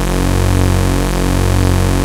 OSCAR 1  F#2.wav